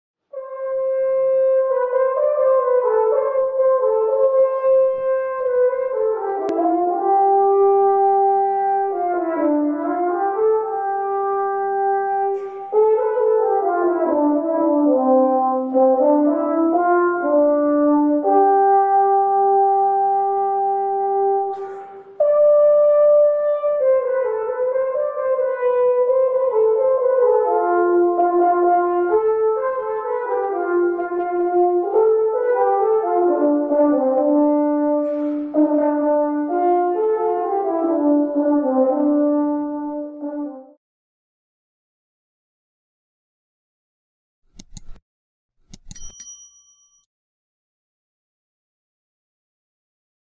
VIENTO METAL
Sonido+De+Trompa+ +Corno+Frances+(1) (audio/mpeg)
TROMPA